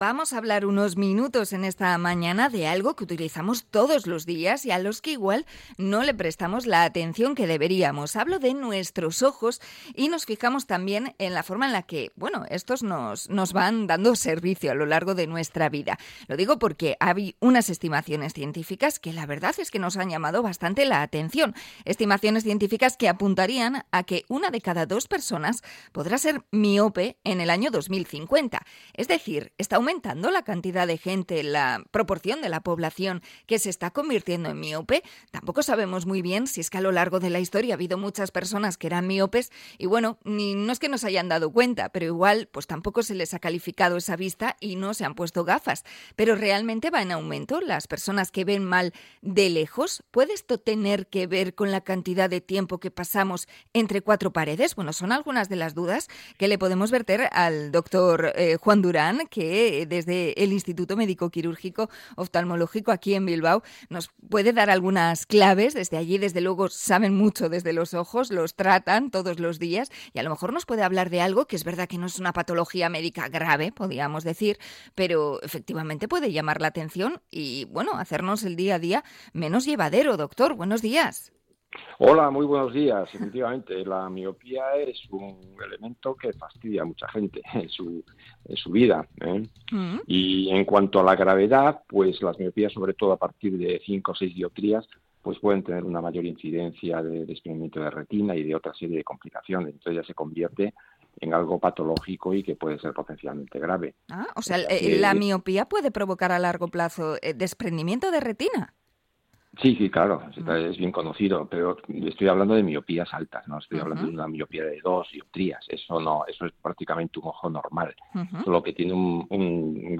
INT.-MIOPIA.mp3